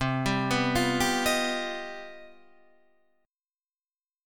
C Minor Major 11th